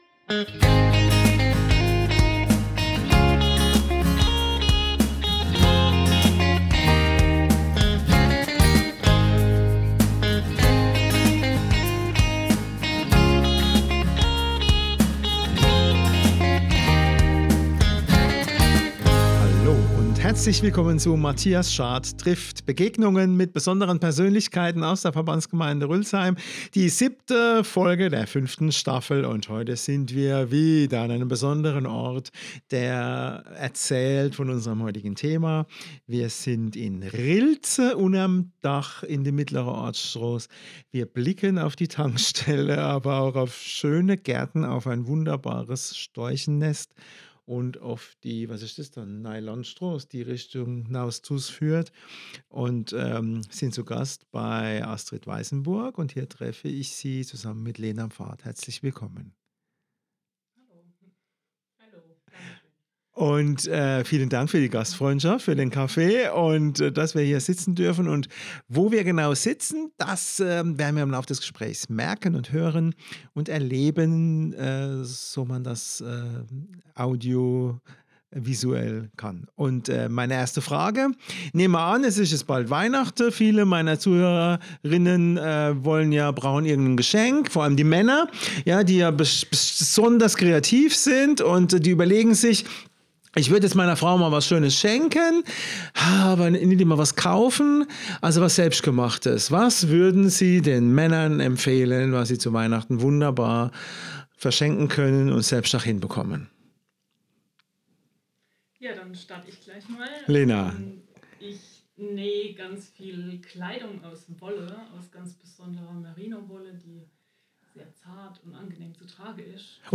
Zu Gast sind sie im Atelier